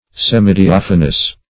Search Result for " semidiaphanous" : The Collaborative International Dictionary of English v.0.48: Semidiaphanous \Sem`i*di*aph"a*nous\, a. Half or imperfectly transparent; translucent.